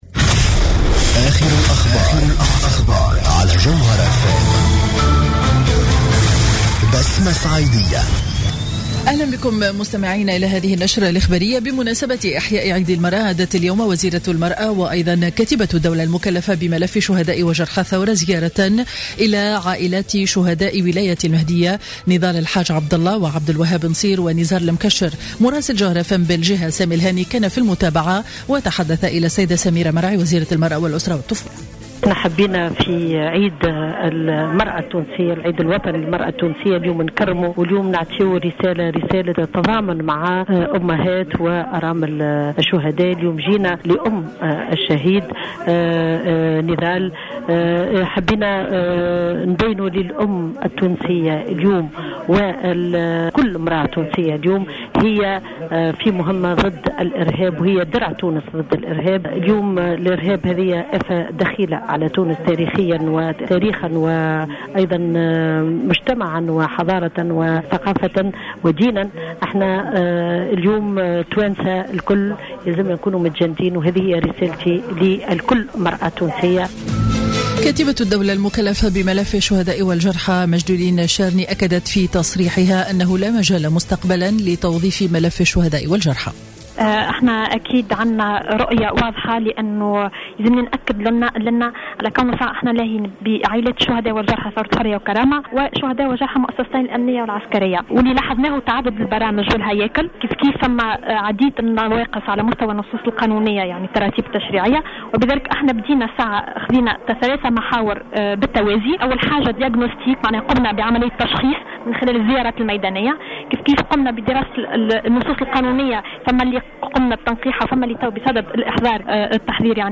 نشرة أخبار منتصف النهار ليوم الأحد 09 أوت 2015